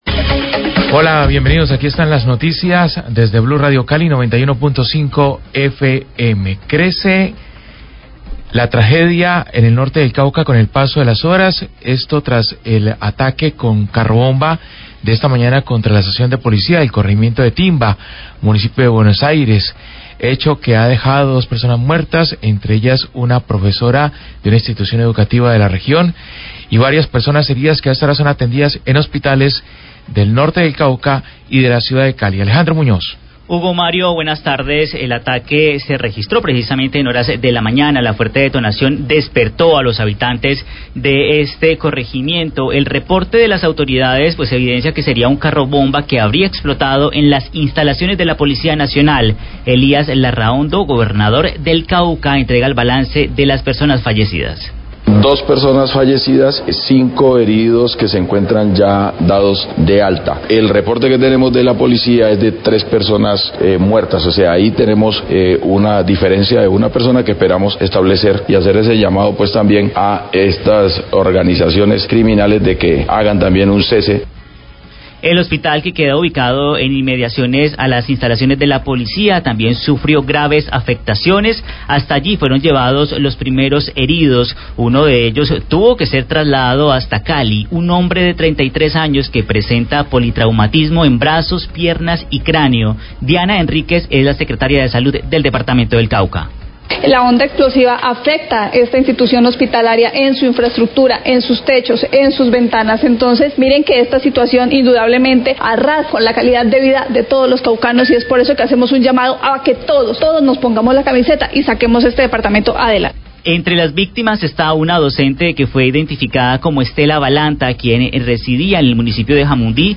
Radio
Las Disidencias delas Farc atacaron con carro bomba la estación de Policía en el corregimiento de Timba-Valle, que dejó como saldo dos muertos y varios heridos. Declaraciones del Gobernador del Cauca, Elias Larrahondo, entrega balance de afectaciones.